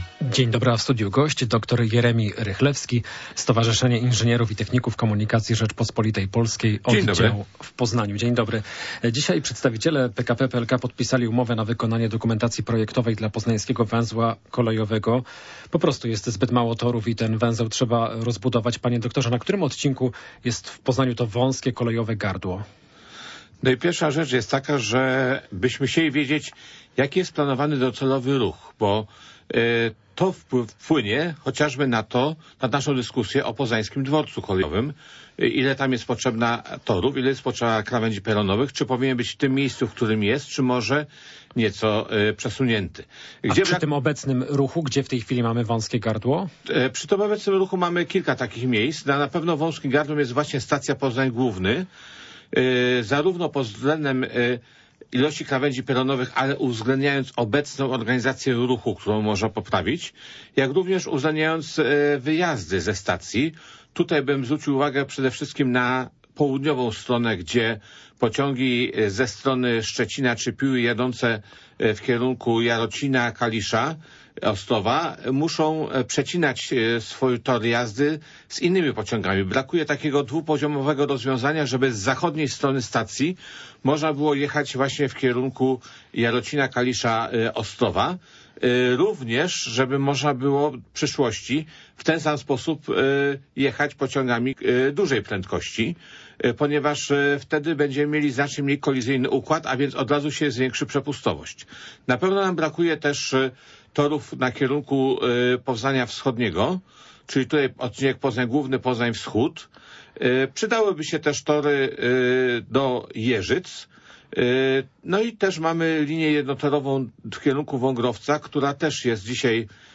Popołudniowa rozmowa